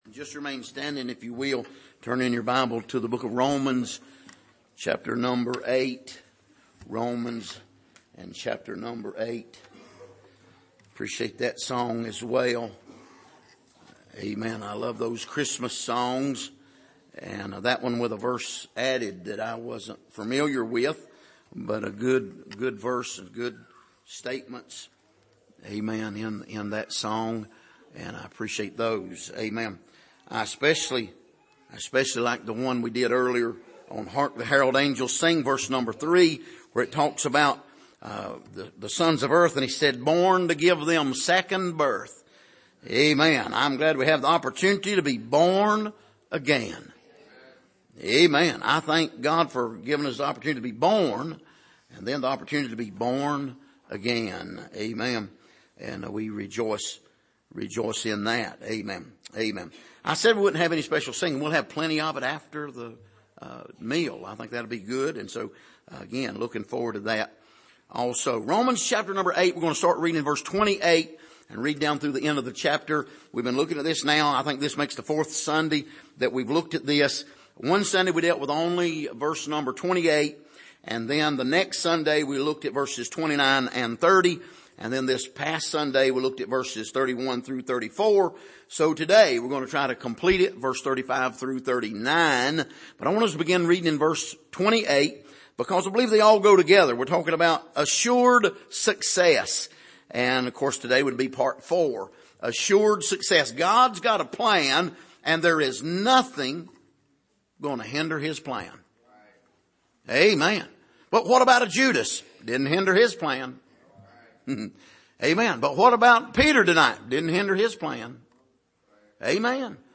Passage: Romans 8:35-39 Service: Sunday Morning